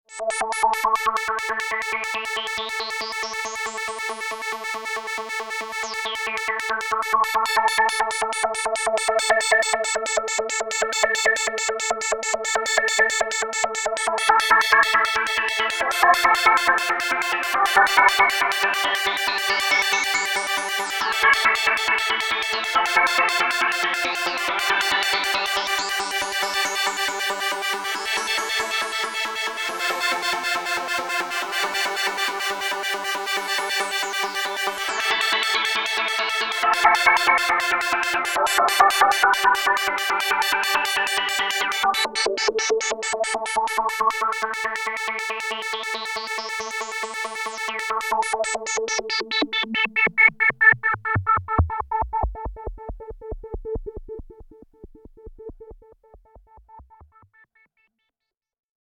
Kawai K3 - digital waves into analogue filter
:play Kawai K3 LFO:
kawai_k3_-_sounds_demo_-_lfo.mp3